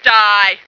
flak_m/sounds/female2/int/F2die.ogg at trunk